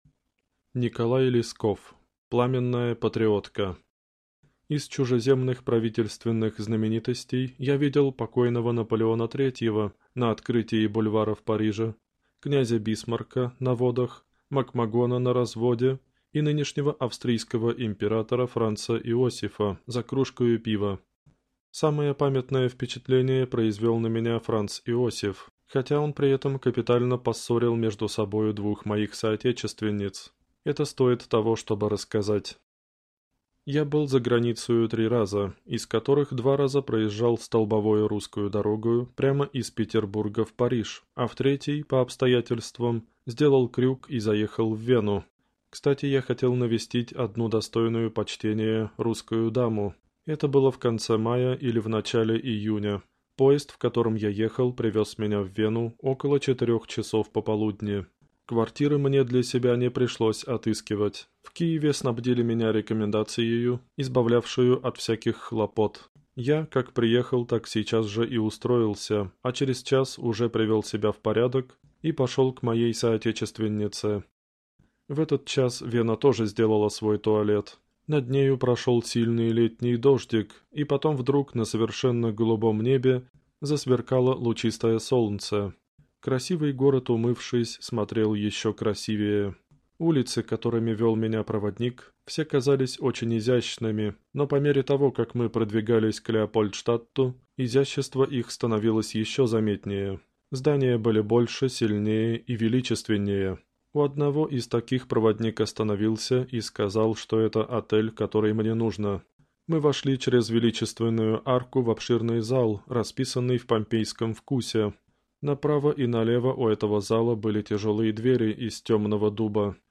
Аудиокнига Пламенная патриотка | Библиотека аудиокниг